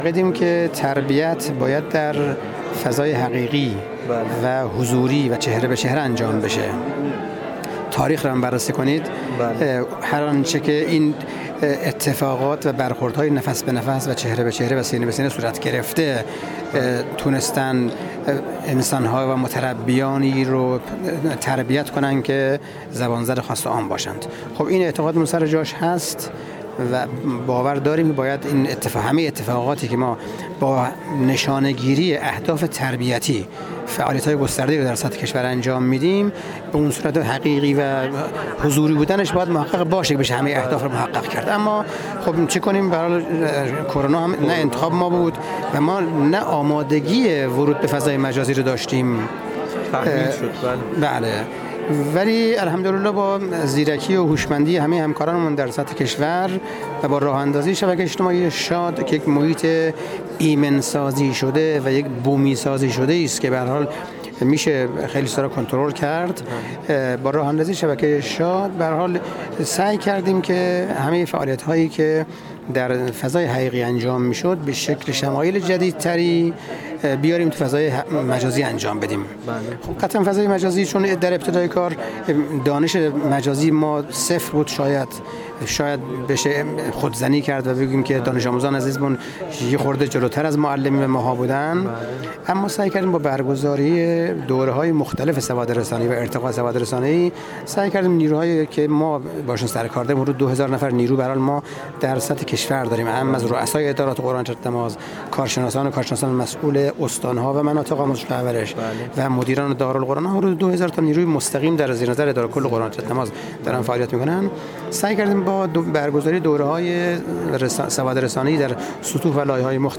در همین راستا میکائیل باقری، مدیرکل قرآن، عترت و نماز وزارت آموزش‌ و پرورش در گفت‌و‌گو با ایکنا به تبیین دغدغه تربیت اسلامی پرداخت و اظهار کرد: با تعطیلی مدارس کشور به علت شیوع ویروس کرونا وزارت آموزش‌وپرورش، تعلیم و تربیت را تعطیل نکرد و از طریق شبکه‌های مجازی مختلف و رسانه ملی پیگیری لازم برای جبران این تعطیلی‌ها را سامان‌دهی کرد؛ اما وجود شبکه‌های مجازی گوناگون و پیام‌رسان‌های مختلفی که خانواده‌ها باید برای استفاده دانش‌آموزان استفاده می‌کرد عزم مسئولان آموزش‌وپرورش را جزم کرد تا برای ساماندهی و استفاده از یک شبکه واحد آموزشی با عنوان شبکه شاد یا همان شبکه اجتماعی دانش‌آموزان اقدام کند.